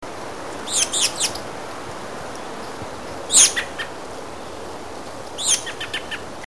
bird.mp3